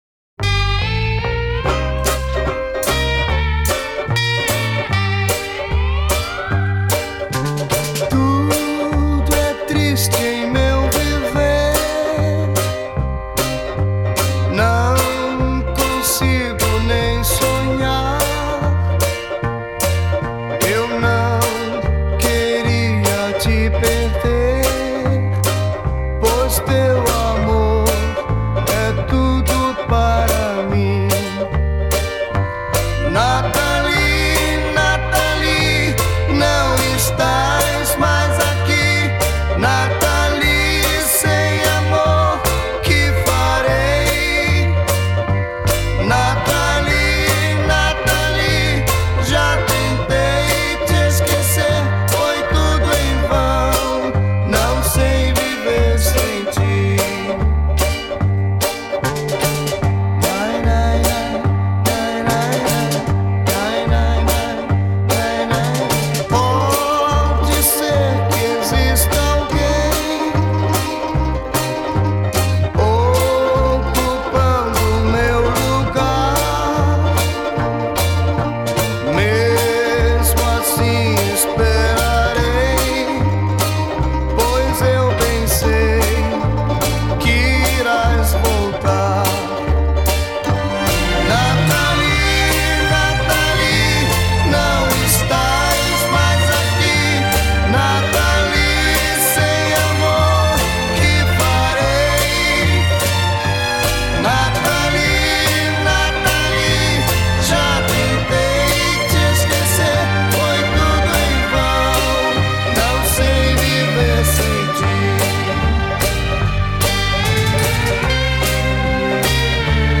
Песня часто звучит у ребят на выпусках 95 квартала, в исполнении женщины, однозначно мулатки, с сильным,прекрасным джазовым голосом. Скорее всего эта песня относится к стилю поп музыки. Музыка энергичная, подвижная, звучит мощно.)